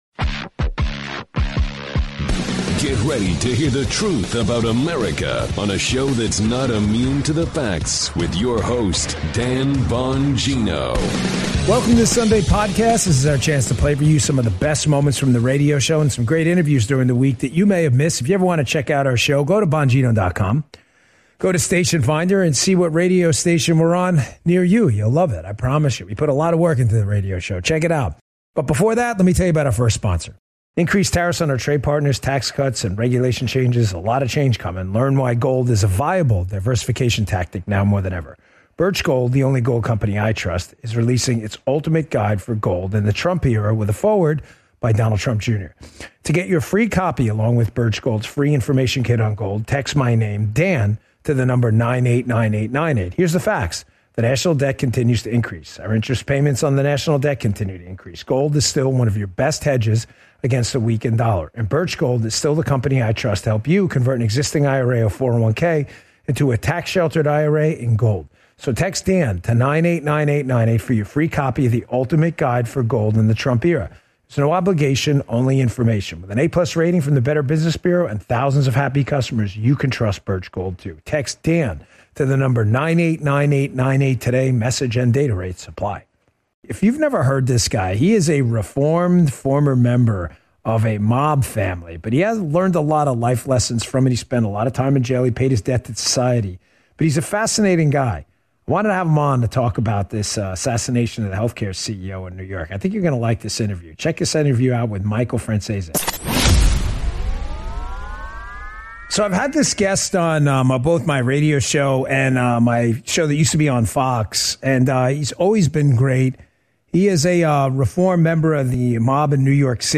The podcast hosted by Dan Bongino features highlights from his radio show and interviews.